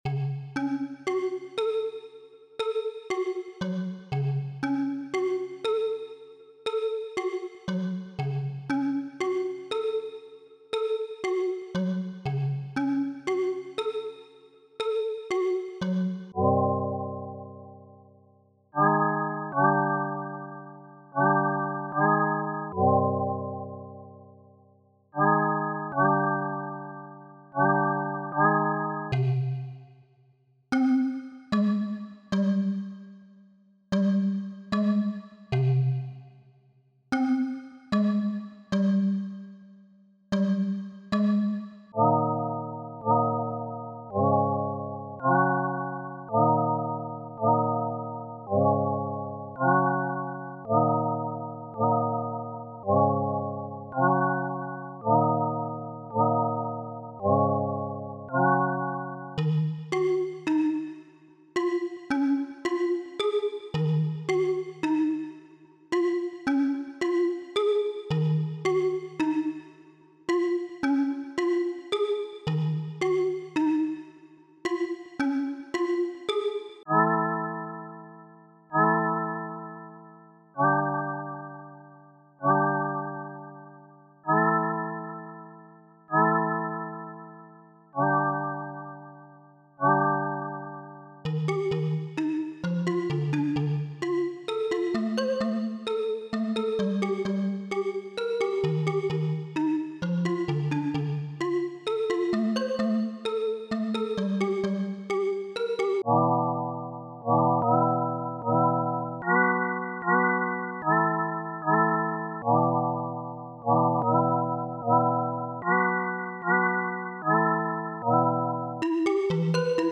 样品包包含精心准备的乐器样品，例如Kalimba，Synth，Chord。
所有声音都经过专业记录，编辑，混合和掌握。
样本包包括精心准备的乐器样本，例如Synth，Chord，Mallet，Keys。
样本包包括精心准备的乐器样本，例如Synth，Pluck，Chord，Pad。
样本包包含精心准备的乐器样本，例如Guitar，Synth和弦。